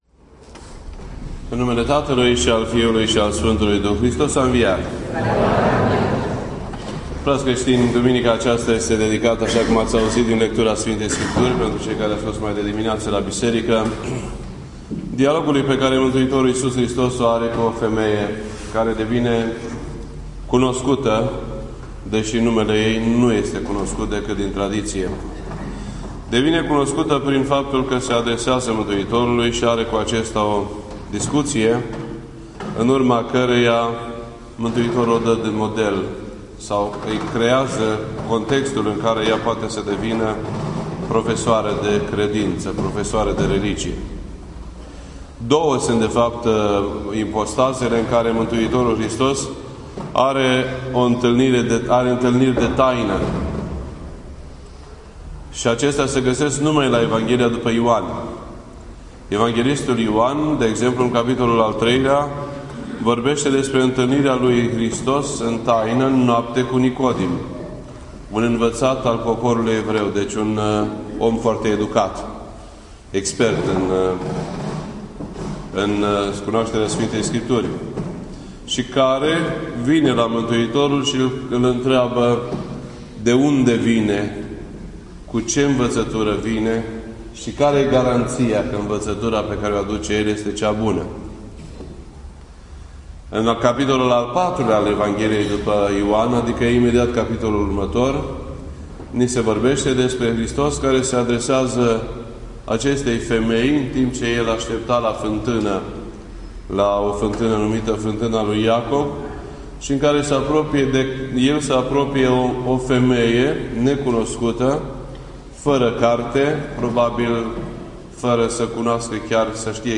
This entry was posted on Sunday, May 18th, 2014 at 12:15 PM and is filed under Predici ortodoxe in format audio.